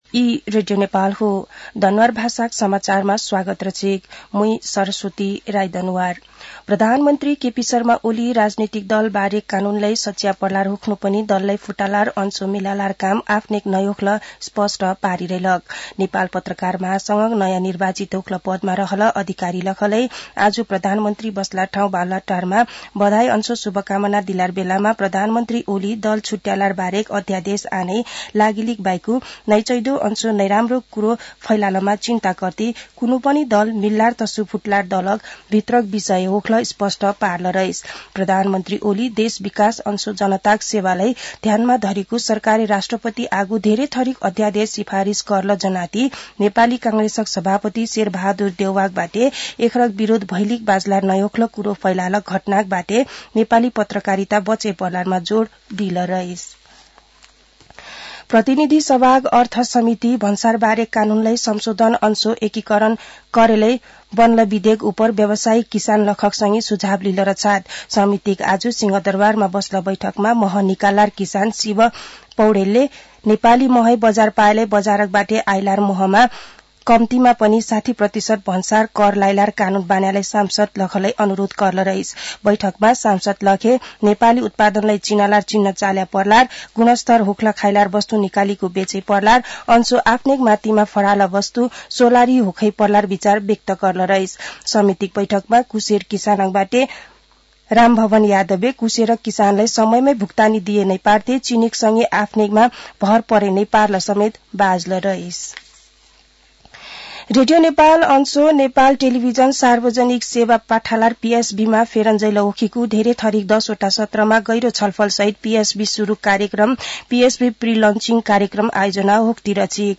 दनुवार भाषामा समाचार : १ माघ , २०८१
Danuwar-news-5.mp3